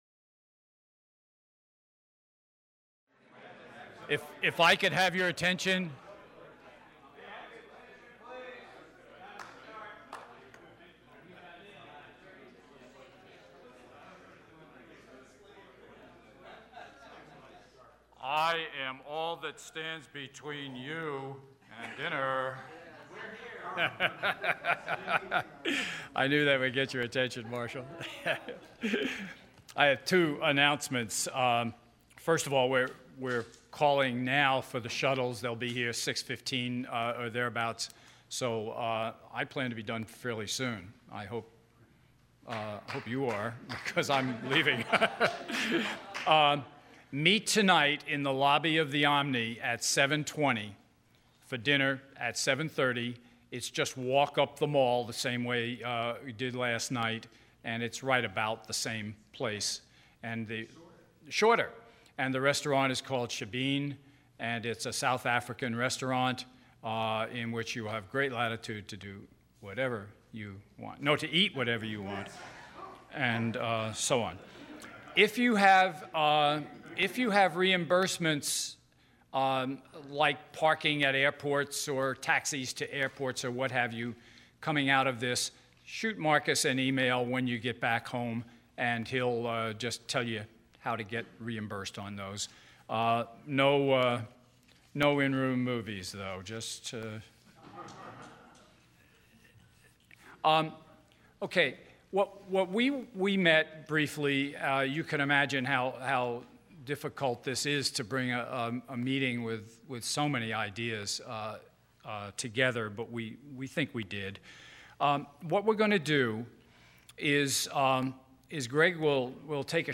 5:45 PM Presented and Facilitated by: Gordon Davies, former Director of State Council of Higher Education for Virginia, 1977–97